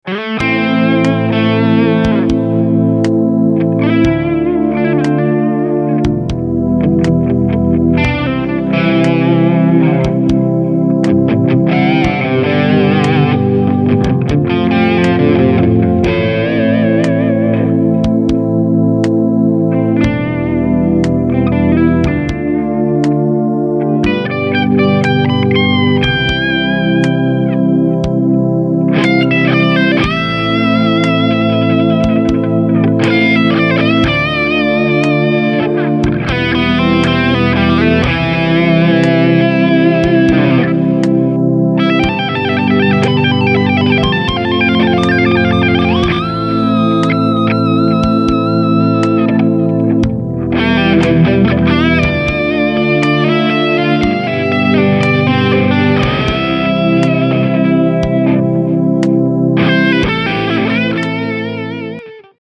-     Instrument : Guitares lead et rythmique.
Le principe : tous les participants téléchargent une back-track commune, enregistrent un solo dessus et postent le mix sur le site.
Défi_2 : un défi purement mélodique.